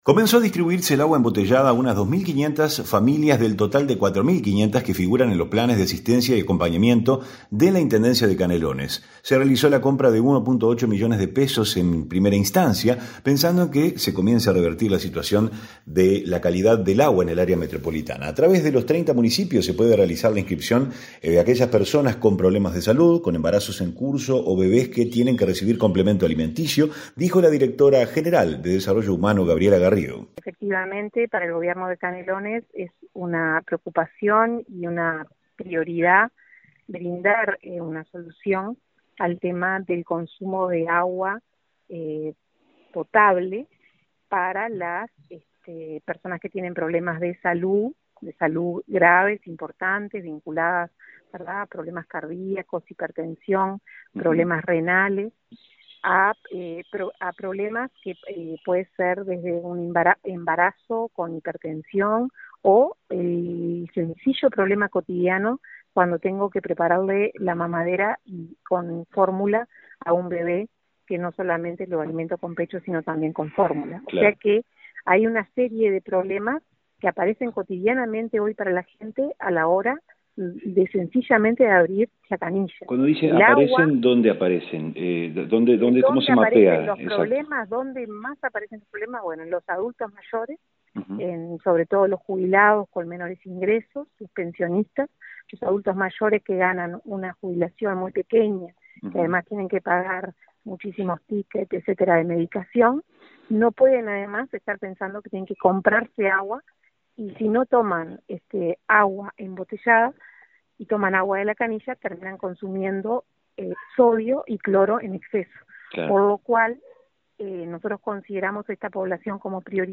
REPORTE-PLANES-AGUA.mp3